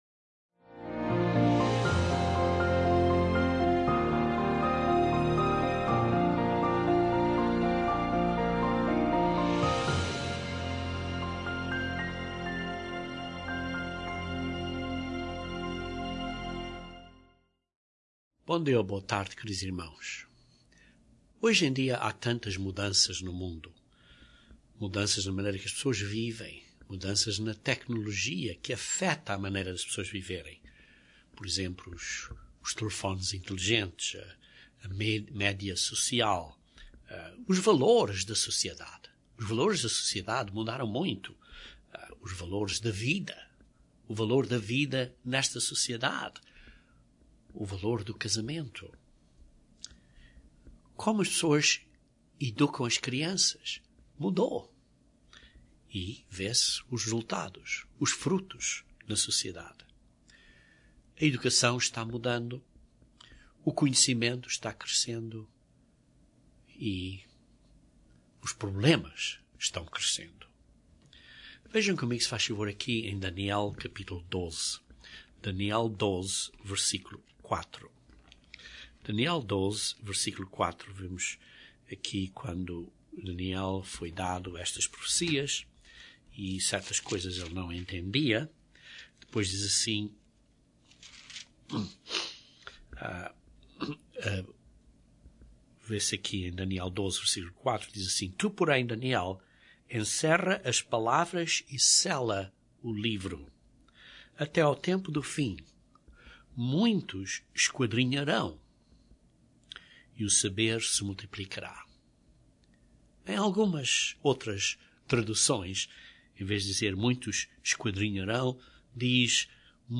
Dedicação é uma firmeza determinada para o trabalho, princípios, família e para com Deus. Este sermão descreve a importância de sermos completamente dedicados a Deus.